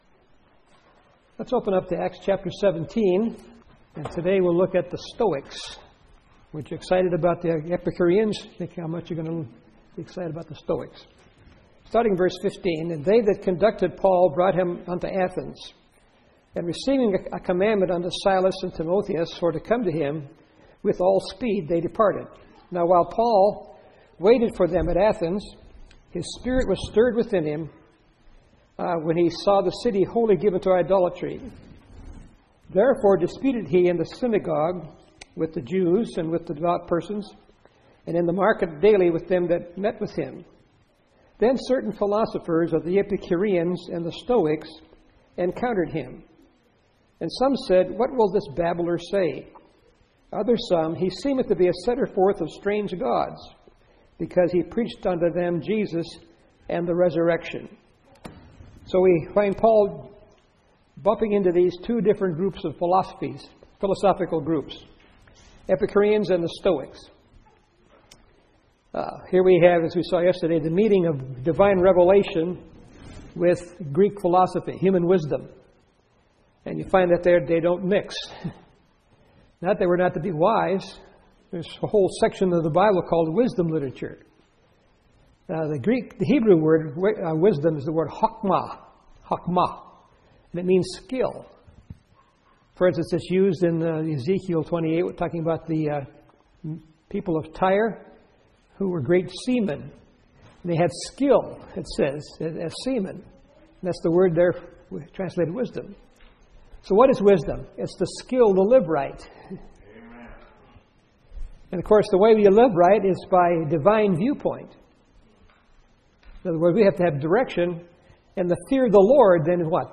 Series: 2013 August Conference Session: Morning Session